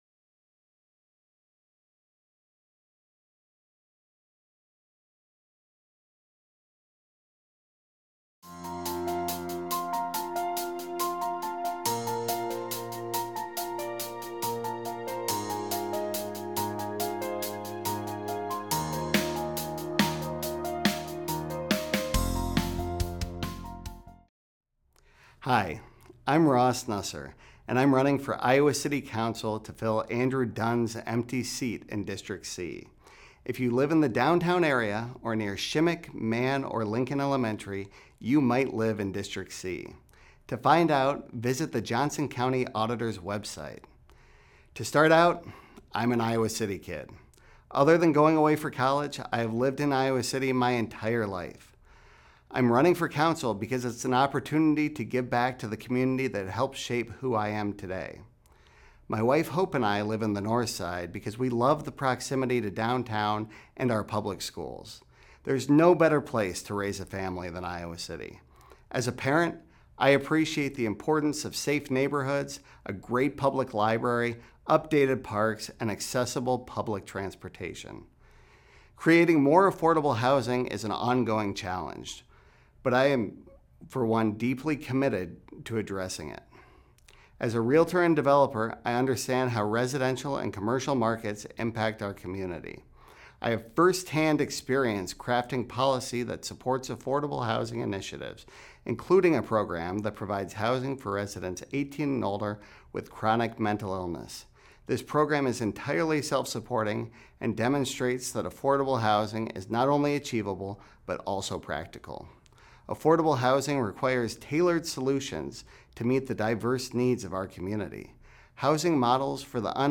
City Channel 4's Meet the Candidates project invited all of the registered candidates for the February 4th Iowa City City Council primary election to come to our studio to present themselves to voters in five minutes or less. Candidates appear in the order they responded to the invitation.